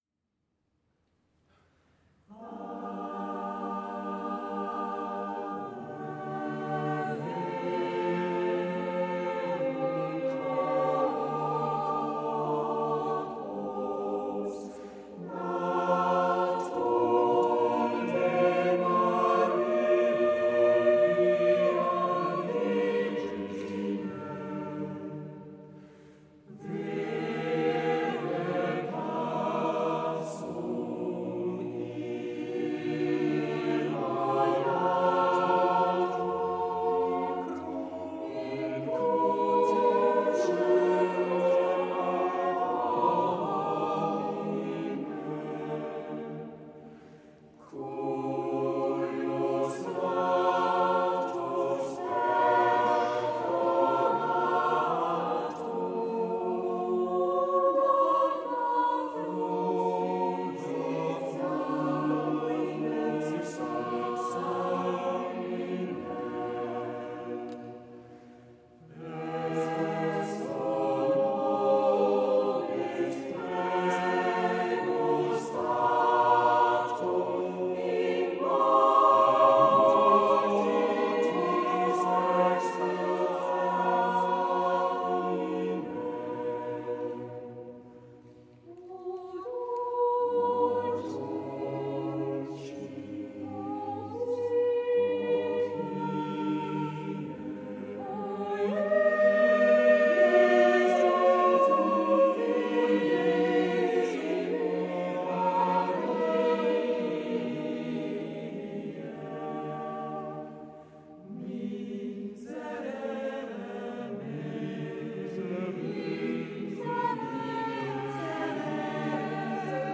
Klang - Chor Vokalensemble Capella Moguntina, Mainz